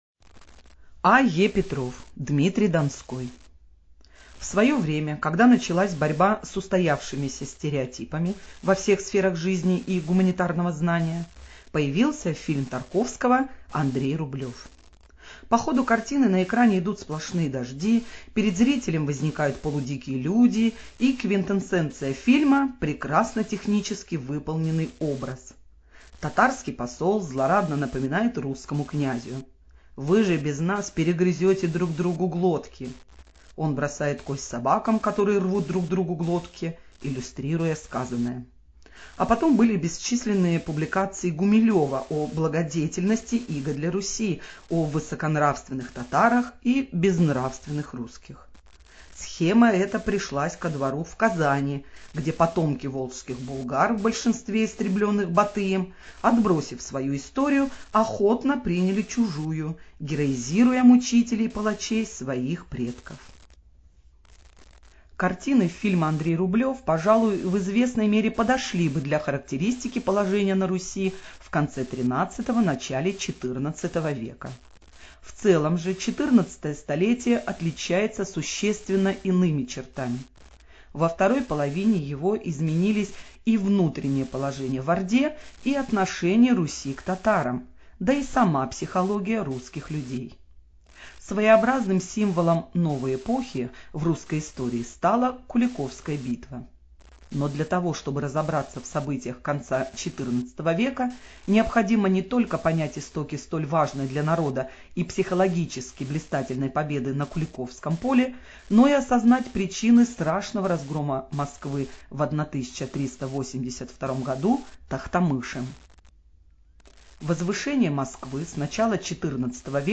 Студия звукозаписиНовгородская областная библиотека для незрячих и слабовидящих "Веда"